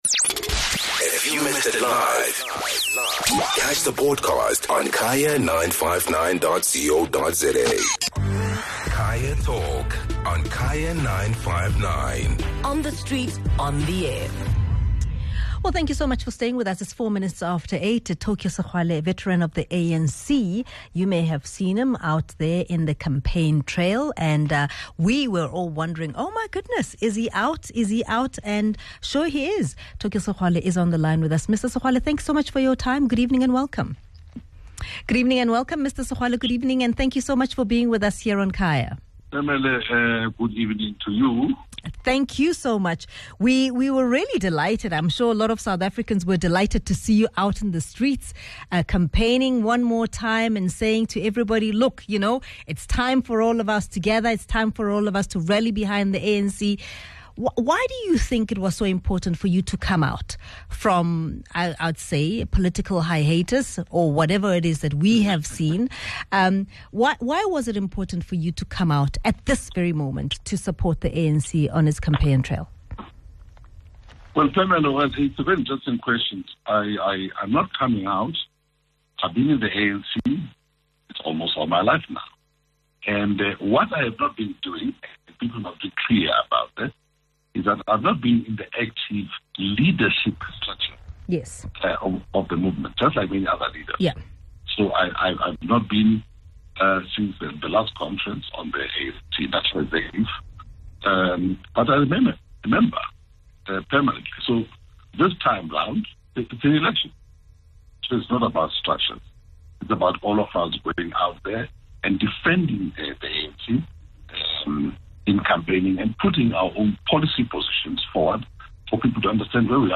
The Gauteng ANC, in a bid to raise its numbers has former provincial premier Tokyo Sexwale strengthen its campaign for the upcoming elections. Most listeners and callers are concerned that all these veterans campaigning were bashing the same political party before, so what changed?